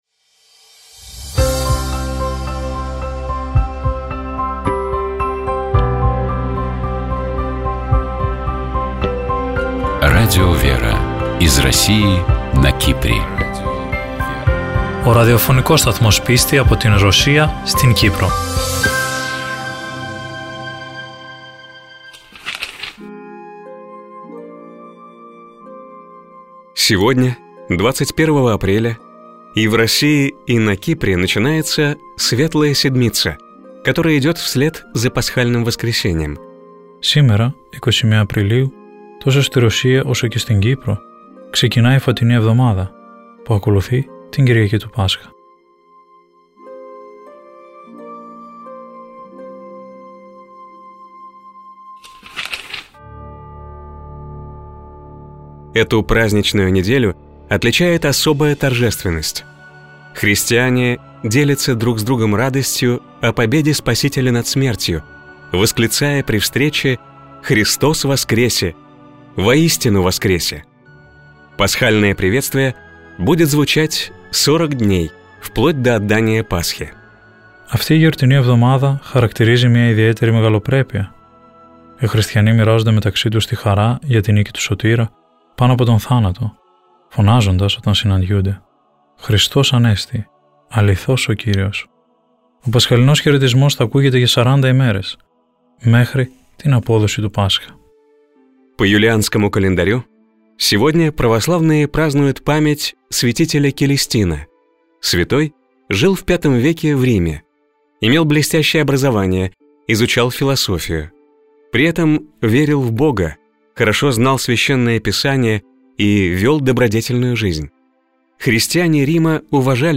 По благословению митрополита Лимассольского Афанасия (Кипрская Православная Церковь) в эфире радио Лимассольской митрополии начали выходить программы Радио ВЕРА. Популярные у российского слушателя программы переводятся на греческий язык и озвучиваются в студии Радио ВЕРА: «Православный календарь», «Евангелие день за днем», «Мудрость святой Руси», «ПроСтранствия», «Частное мнение» и другие.